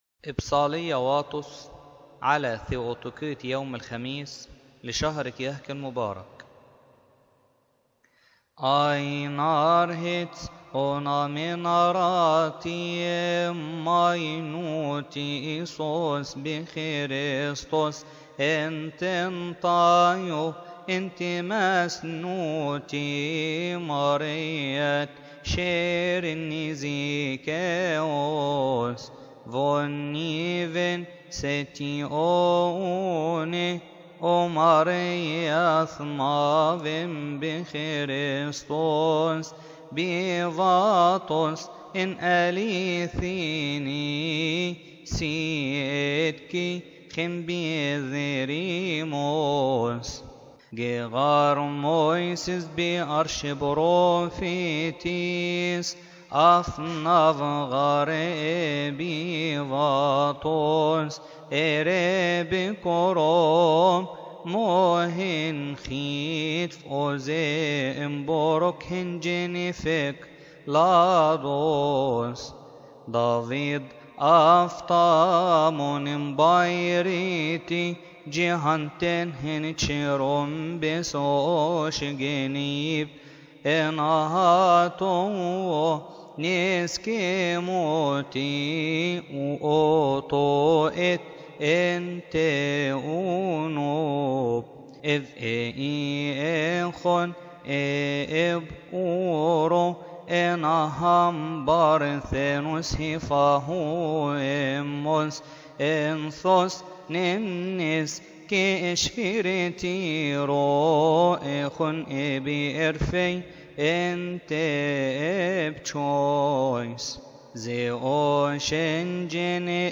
تقال في تسبحة نصف الليل بشهر كيهك
المرتل